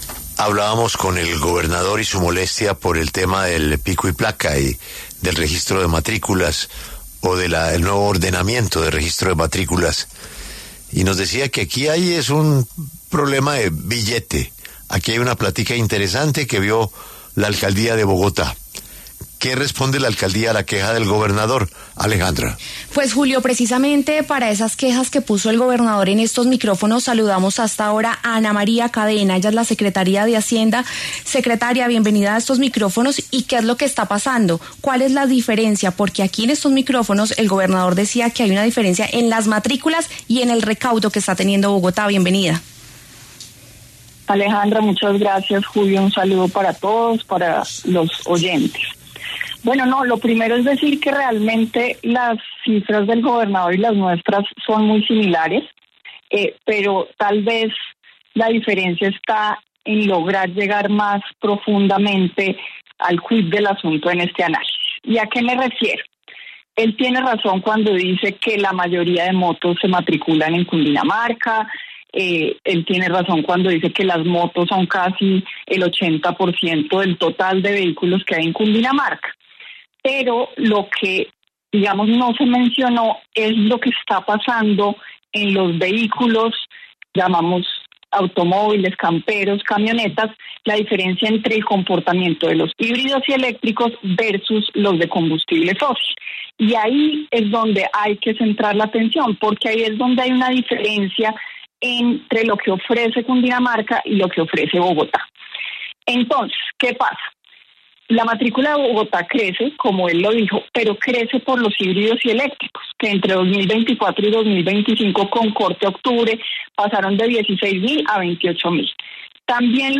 En los micrófonos de La W, la secretaria de Hacienda de Bogotá, Ana María Cadena, salió al paso a las críticas que han hecho a la medida de pico y placa los sábados para los vehículos que no están matriculados en la capital, ya que muchos aseguran que es una medida que solo es para recaudar dinero.